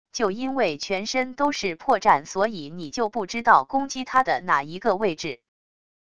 就因为全身都是破绽所以你就不知道攻击他的哪一个位置wav音频生成系统WAV Audio Player